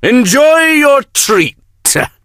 snakeoil_ulti_vo_01.ogg